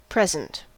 En-us-present-adjective.ogg.mp3